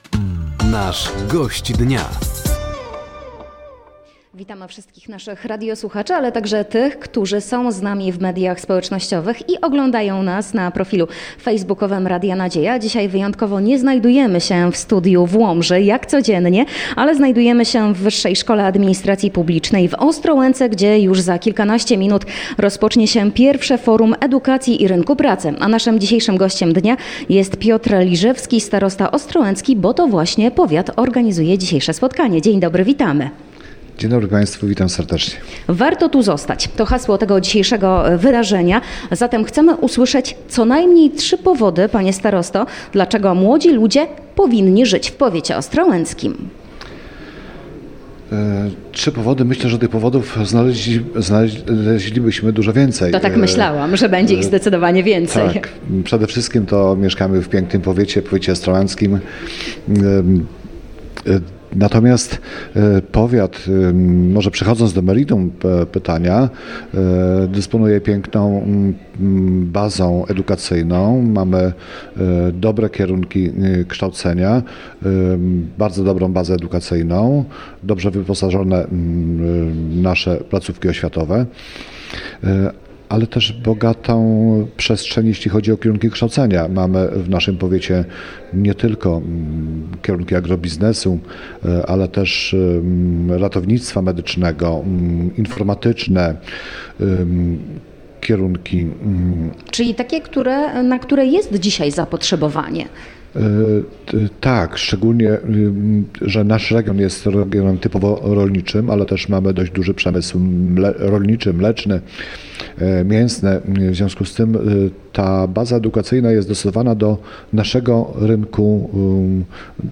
Gościem Dnia Radia Nadzieja był Piotr Liżewski, starosta ostrołęcki. Głównym tematem rozmowy było Forum Edukacji i Rynku Pracy zorganizowane przez Powiat Ostrołęcki.